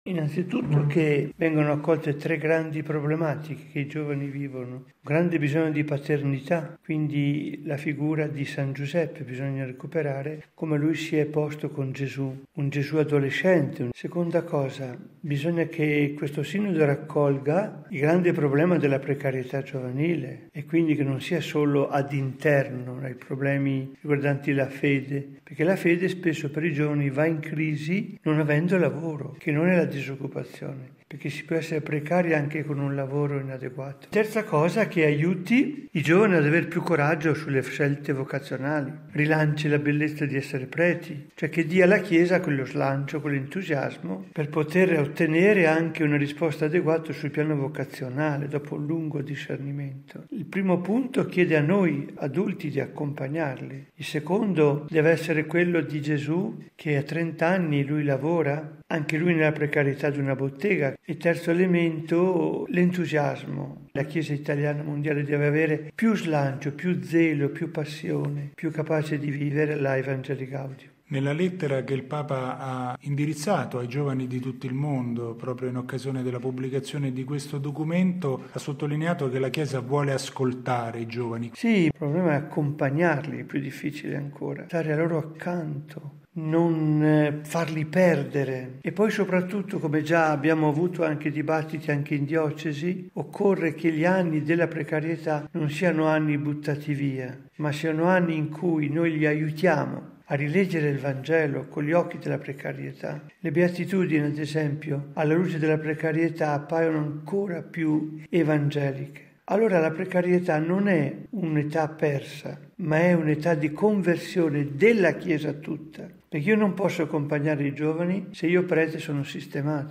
Un avvenimento che può dare un rinnovato slancio a tutta la Chiesa. A dieci giorni dalla pubblicazione del Documento preparatorio per il Sinodo dei Vescovi sui giovani, in programma il prossimo anno, mons. Giancarlo Bregantini si sofferma – ai microfoni della Radio Vaticana – sulle sfide che la gioventù pone alla Chiesa.